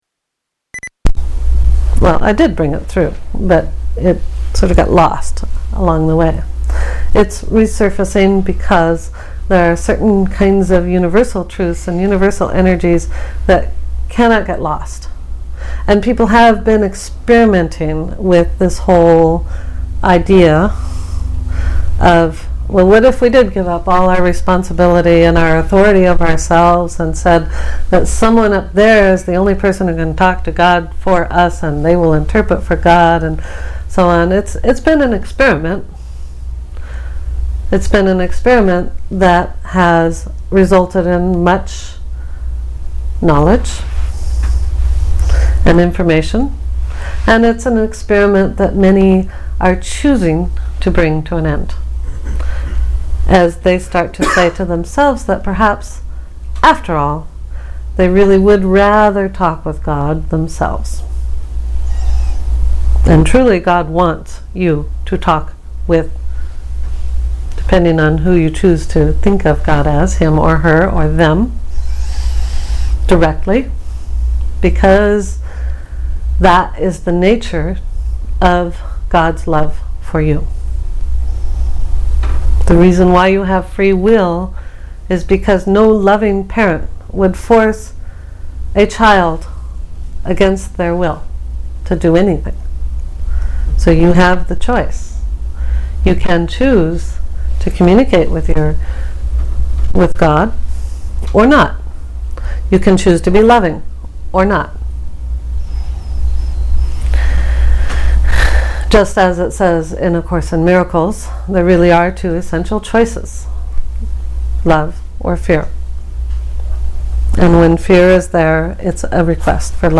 I was a little congested for this channeling, which you can hear, and the recording quality is a bit noisy because there was a low thrumming in the background that apparently only I could hear during the channeling, but which is quite audible on the recording.